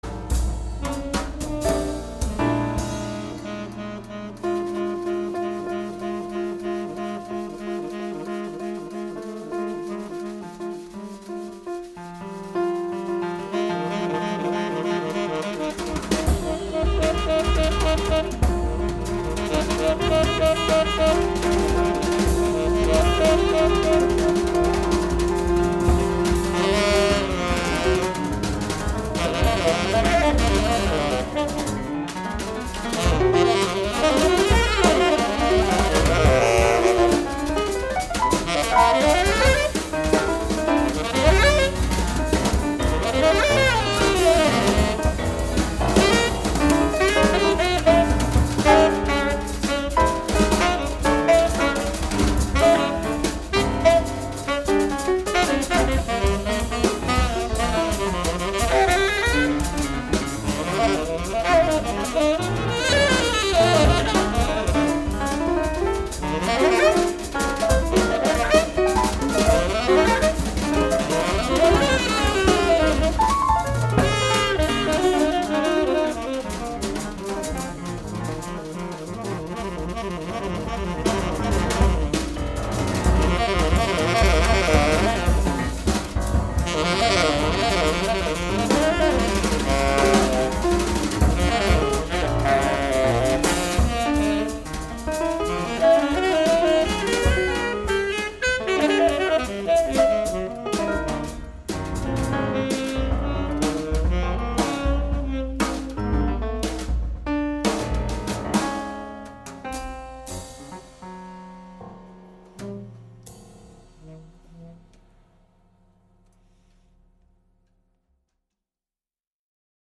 American pianist
saxophonist & clarinetist